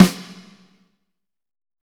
Index of /90_sSampleCDs/Northstar - Drumscapes Roland/DRM_Pop_Country/SNR_P_C Snares x